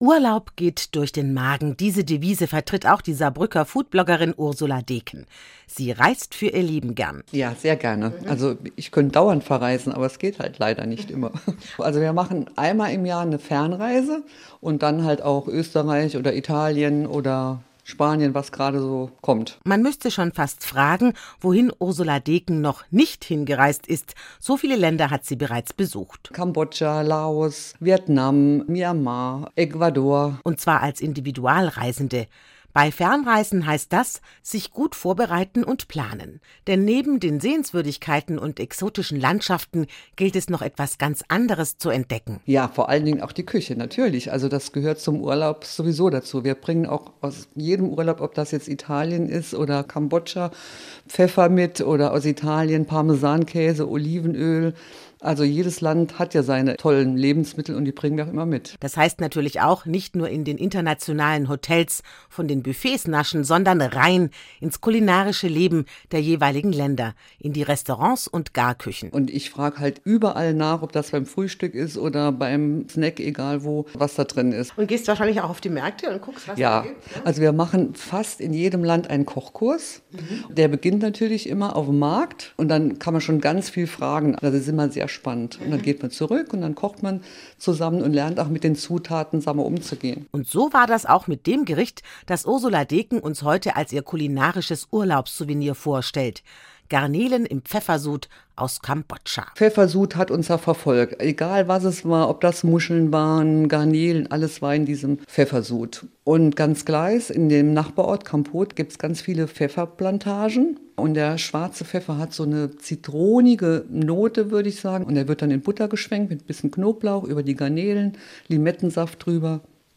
Zu hören war es am Samstag, 19.05.2018 11:00 Uhr auf SR 3 Saarlandwelle in der Sendung Bunte Funkminuten, ein Service-Magazin am Vormittag im Saarland.